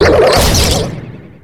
Cri de Noacier dans Pokémon X et Y.